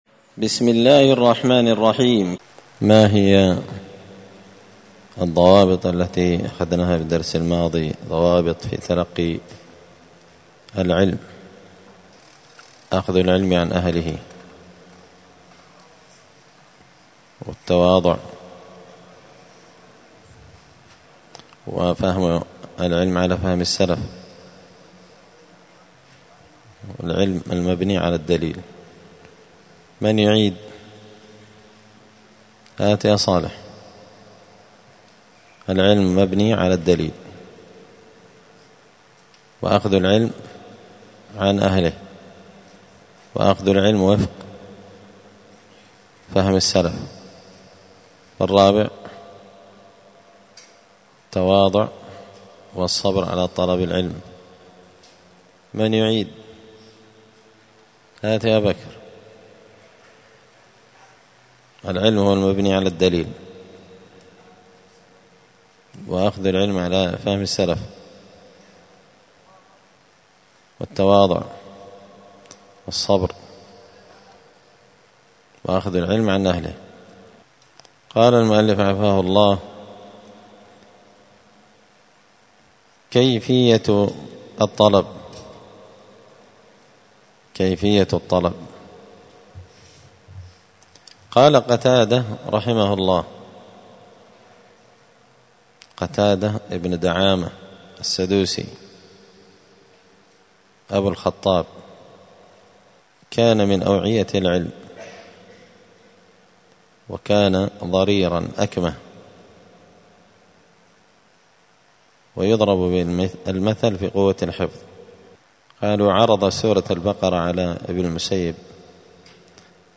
دار الحديث السلفية بمسجد الفرقان بقشن المهرة اليمن
*الدرس الحادي والعشرون (21) تابع لباب ضوابط في تلقي العلم*